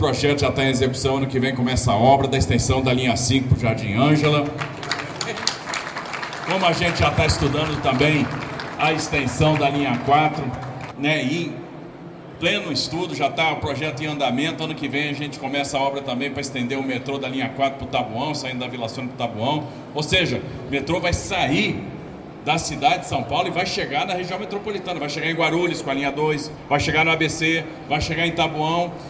Desta vez, as previsões foram feitas neste sábado, 30 de setembro de 2023, pelo Governador Tarcísio de Freitas, durante evento de chegada do Tatuzão Sul à futura estação Perdizes, da linha 6-Laranja de metrô.